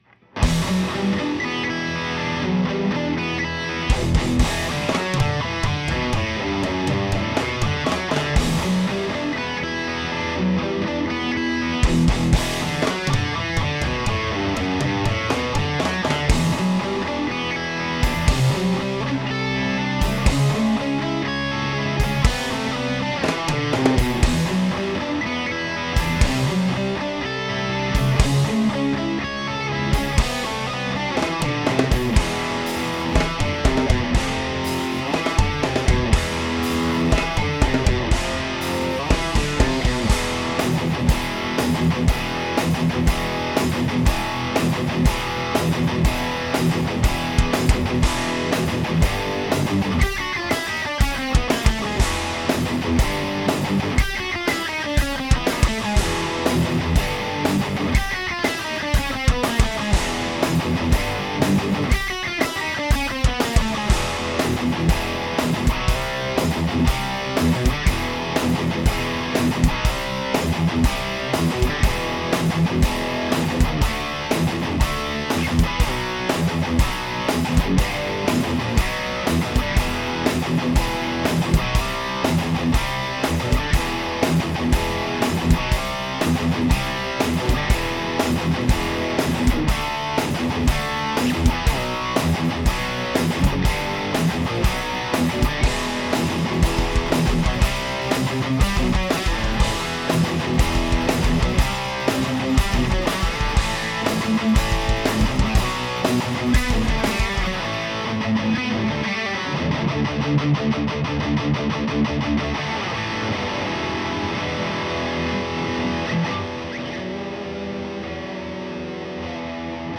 Nailed the classic Megadeth vibe!!!!